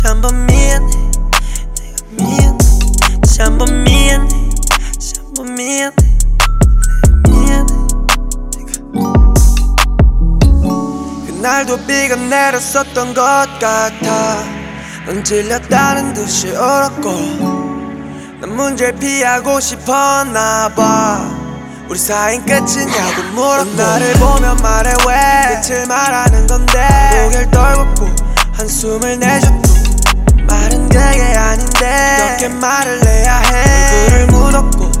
Жанр: Рэп и хип-хоп
# Korean Hip-Hop